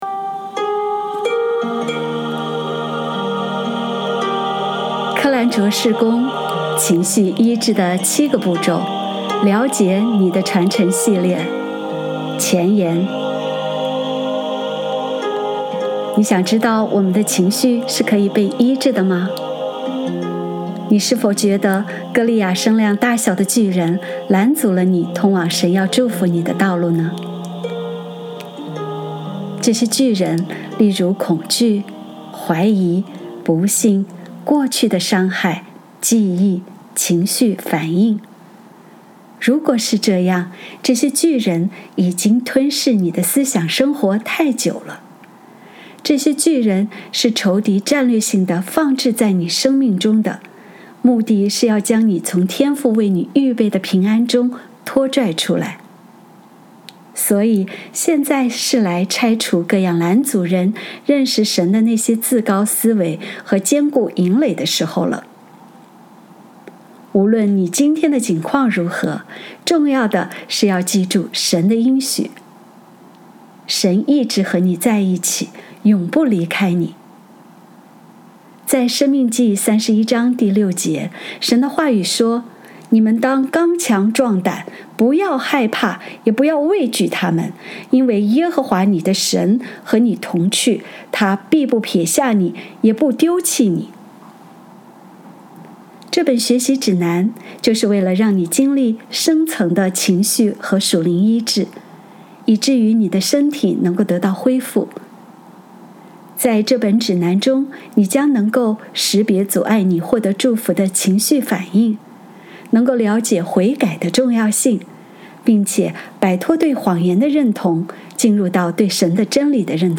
语音录制